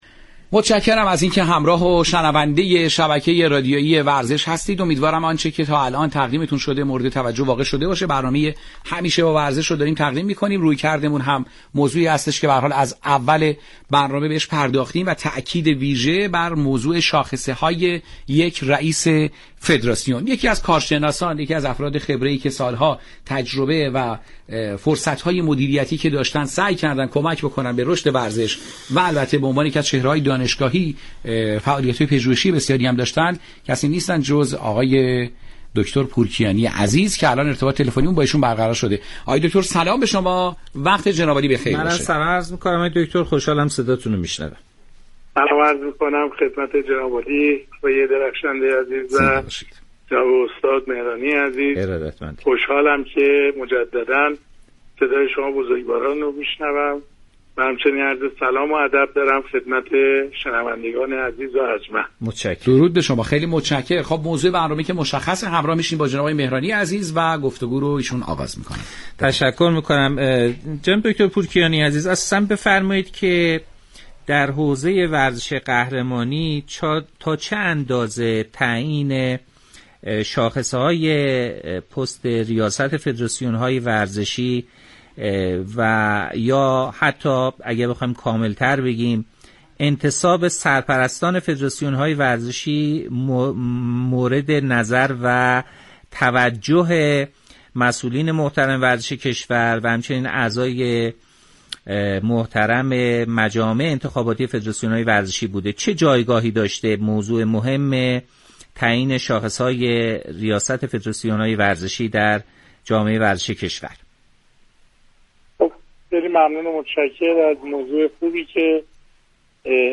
برنامه رادیویی ورزش
مصاحبه